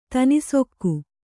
♪ tanisokku